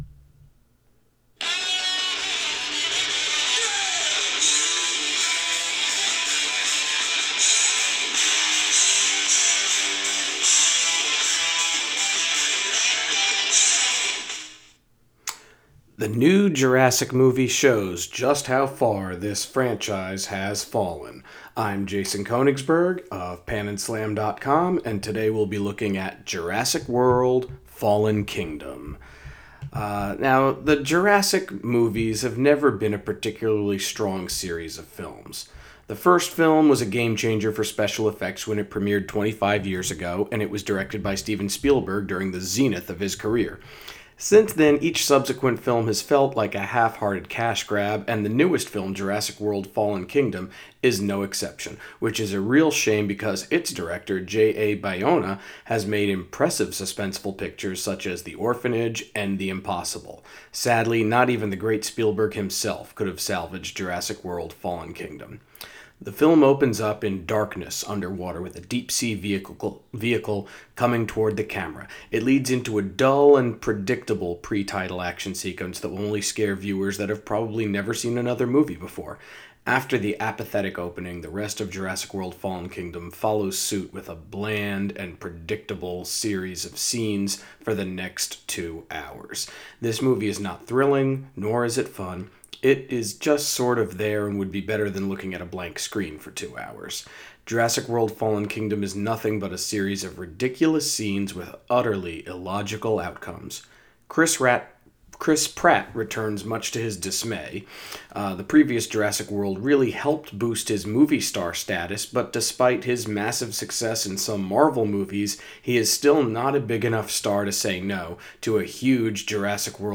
Movie Review: Jurassic World: Fallen Kingdom